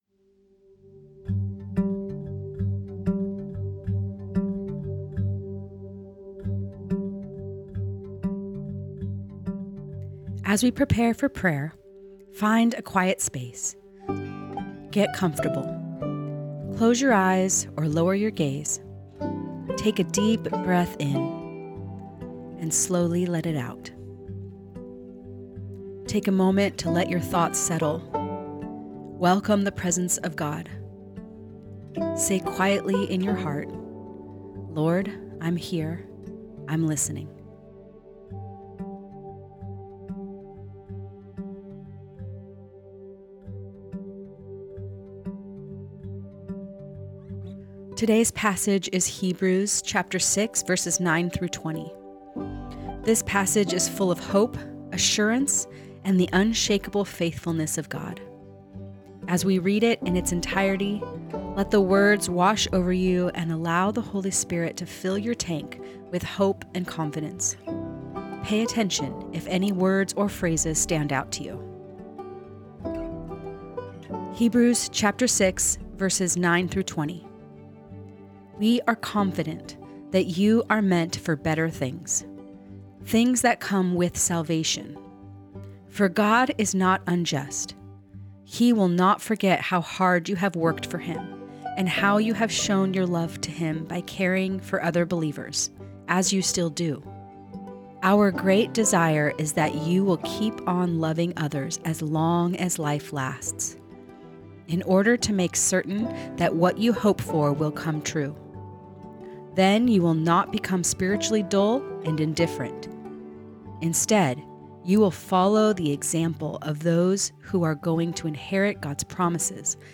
Guided Listening Practice Prepare Find a quiet space.